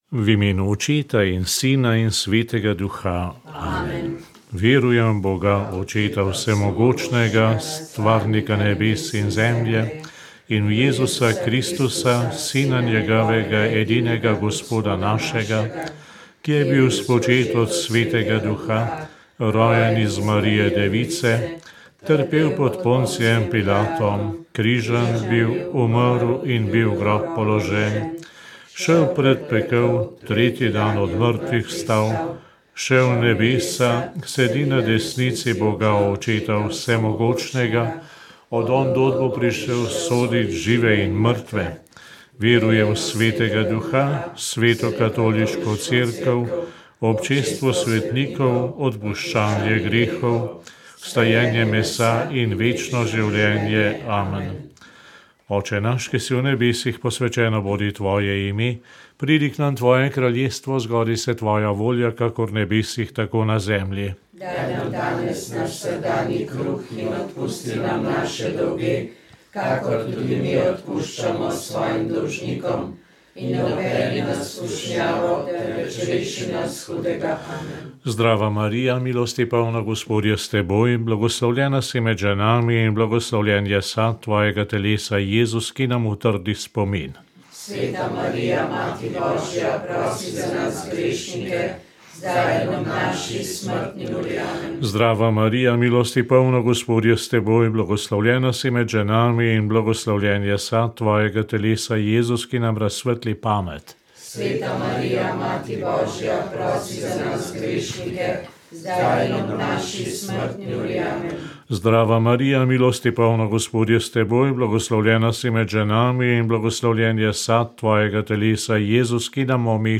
Molitev rožnega venca za domovino je vodil ljubljanski nadškof Stanislav Zore.